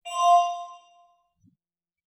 InfoComputerStartup.wav